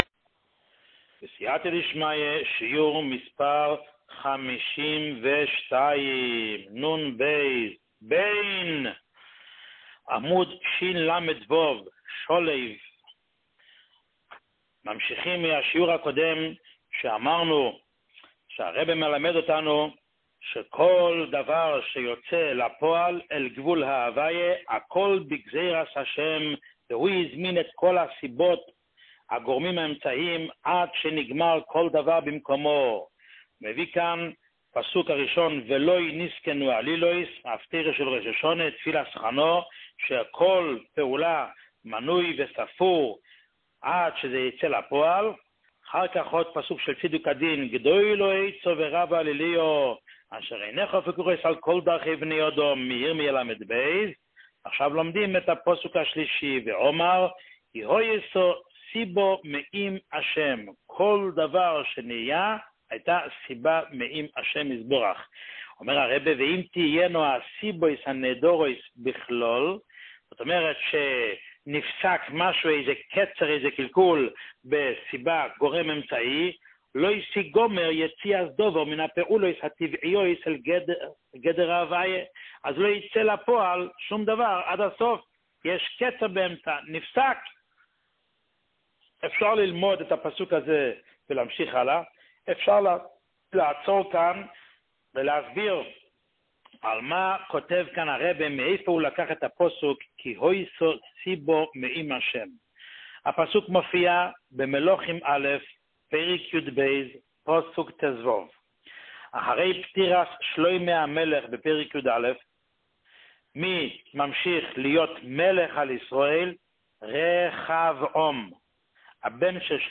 שיעור 52